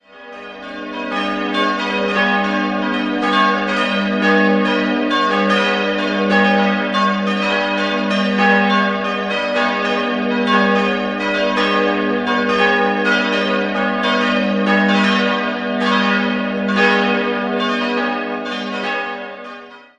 Von der alten Kirche ist ansonsten nur noch der barocke Chorraum von 1707 erhalten. 4-stimmiges Gis-Moll-Geläute: gis'-h'-cis''-dis'' Die größere Glocke wurde 1805 von Regnault gegossen, die drei kleineren entstanden 1973 bei Rudolf Perner in Passau.